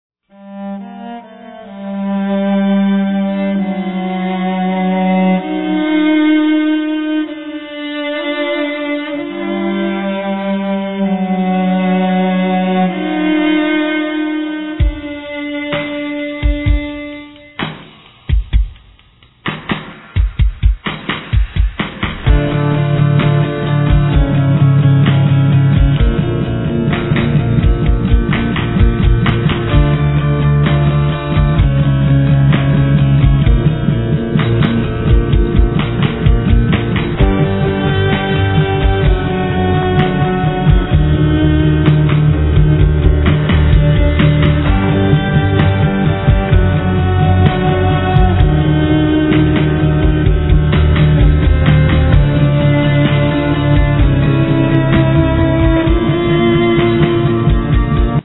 静かながらもジワリジワリとリスナーの心の襞にノスタルジックな情感が染み込んでくるようです。
Acoustic Guitar
Alto Vocals
Drums, Percussion
Soprano Vocals
Tenor Vocals